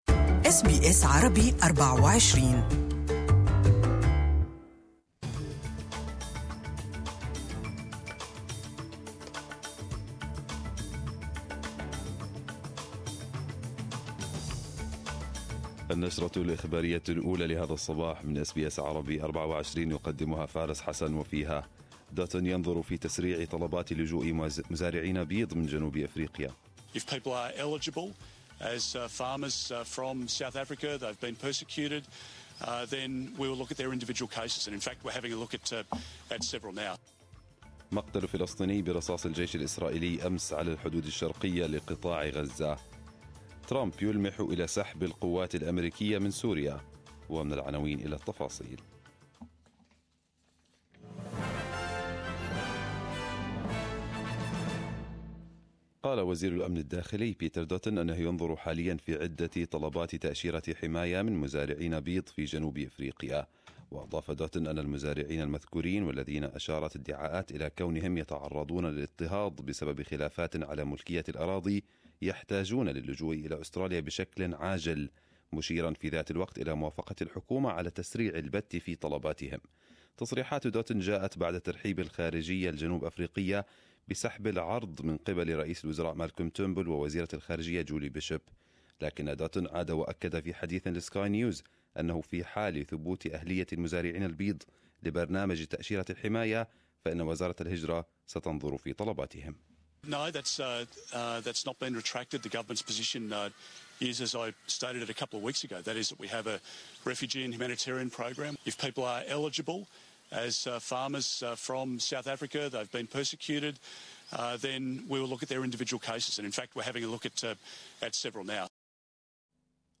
Arabic News Bulletin 04/04/2018